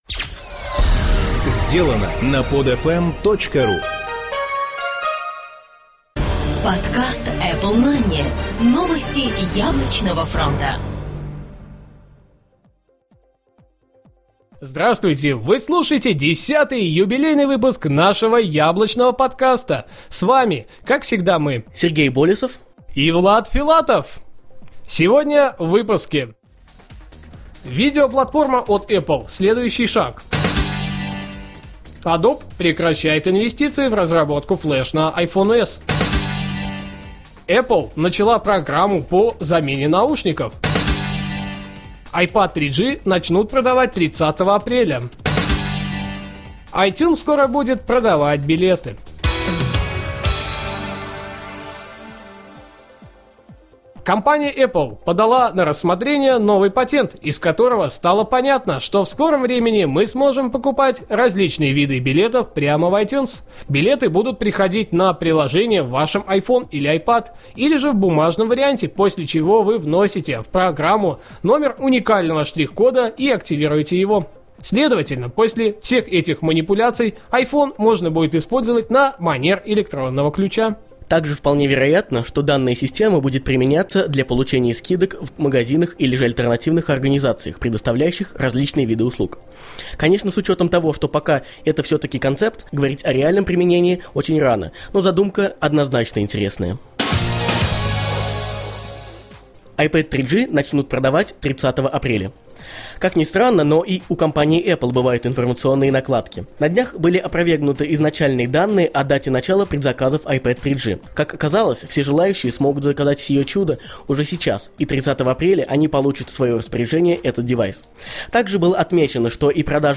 Жанр: новостной Apple-podcast
Битрейт аудио: 80-96, stereo